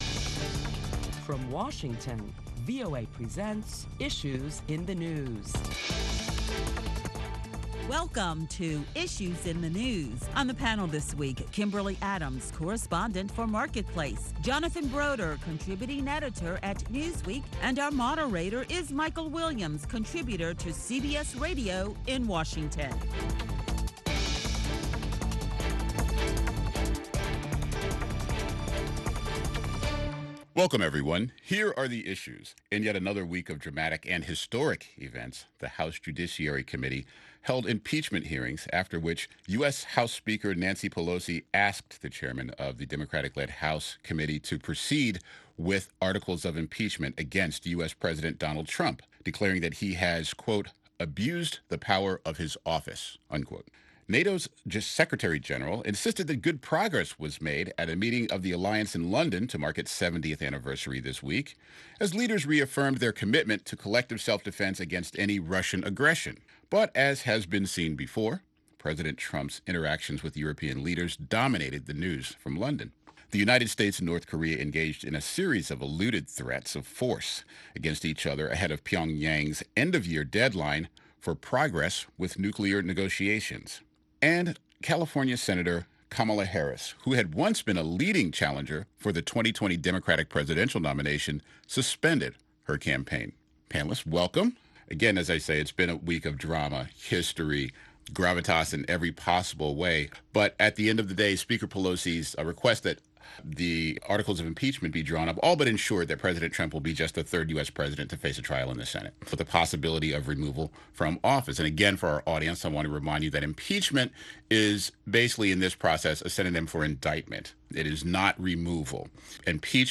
Listen to a panel of prominent Washington journalists as they deliberate the latest top stories that include the Democrat-led House Judiciary Committee proceeds with articles of impeachment against President Donald Trump.